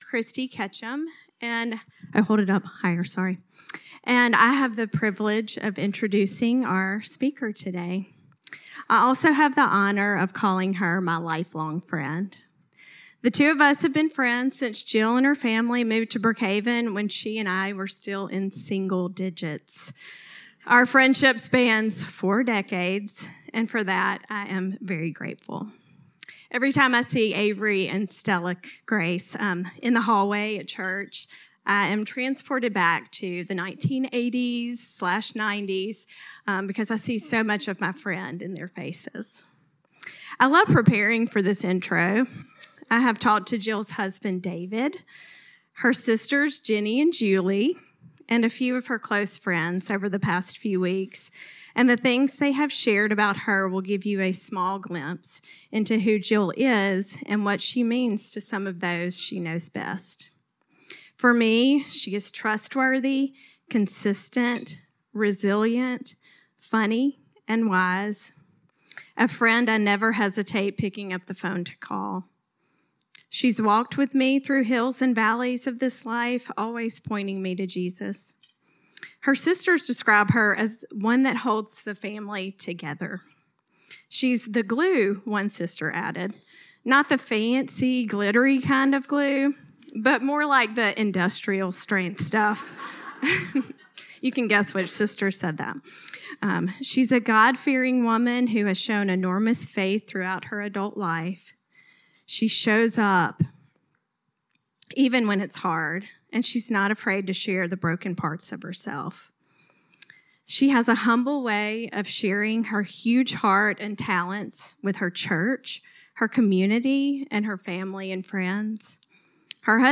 Women’s Ministry Christmas Luncheon